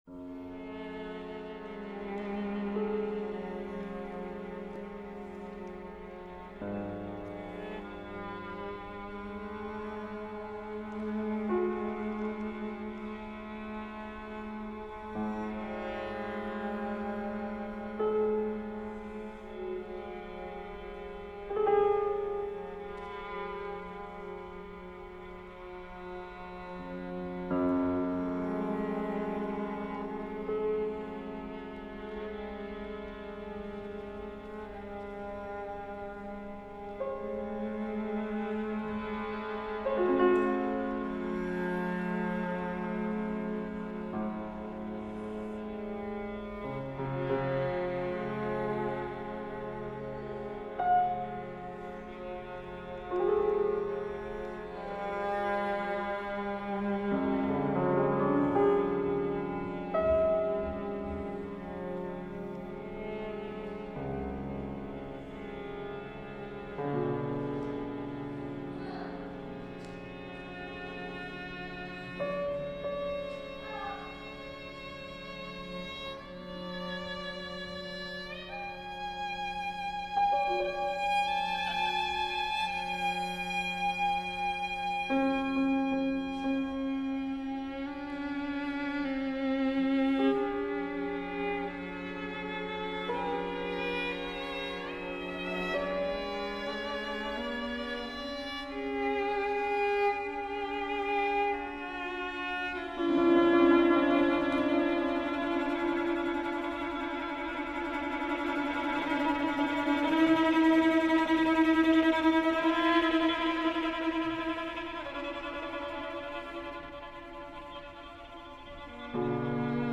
for string quartet and piano